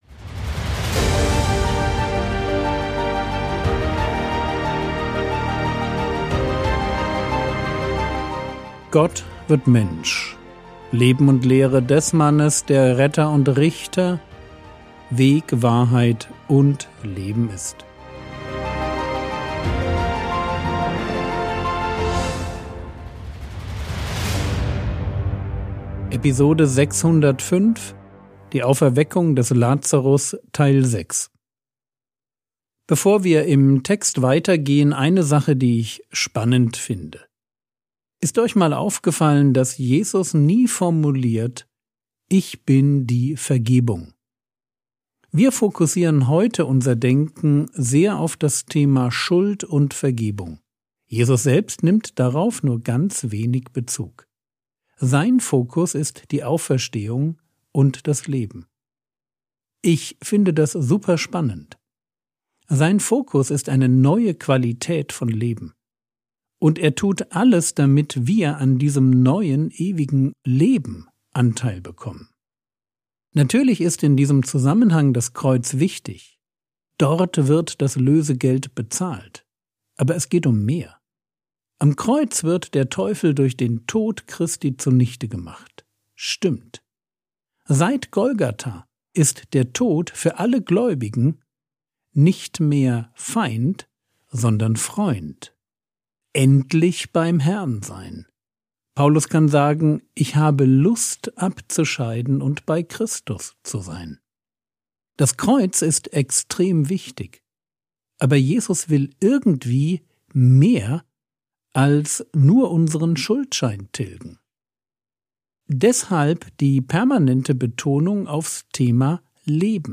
Episode 605 | Jesu Leben und Lehre ~ Frogwords Mini-Predigt Podcast